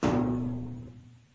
撞击声 " 玻璃撞击 01
描述：一块玻璃用手指尖敲击。
标签： 中空 振铃 玻璃 影响
声道立体声